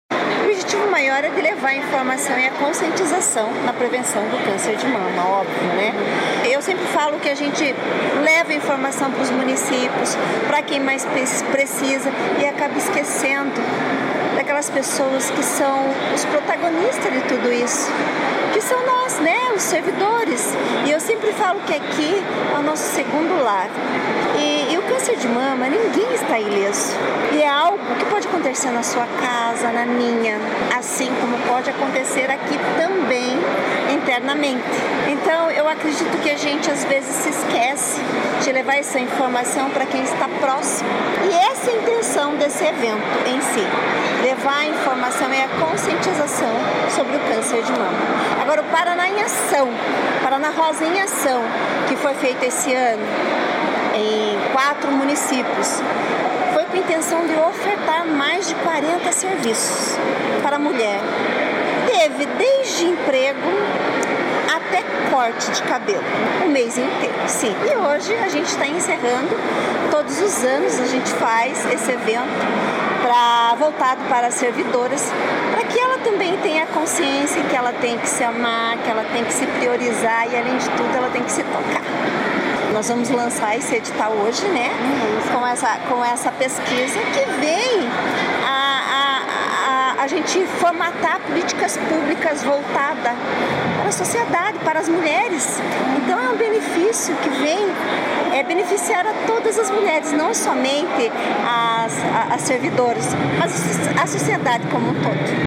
Sonora da primeira-dama do Paraná